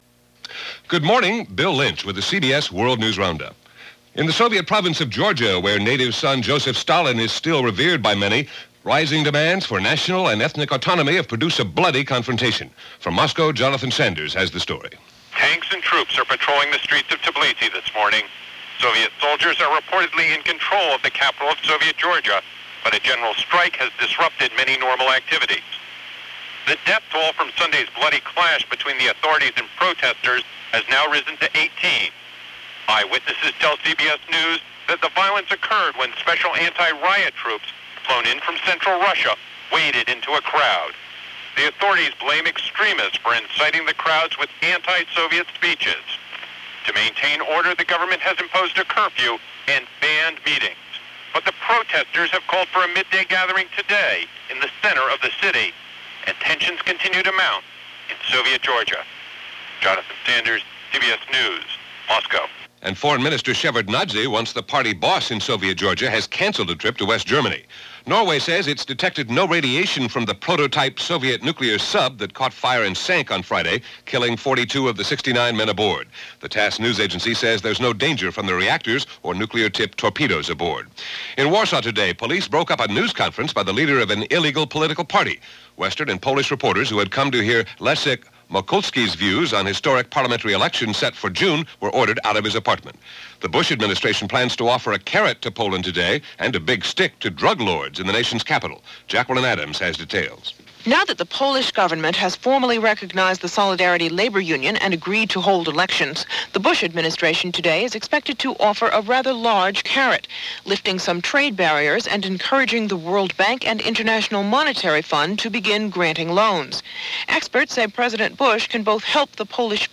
And that’s a small slice of what went on, this April 10, 1989 as reported by The CBS World News Roundup.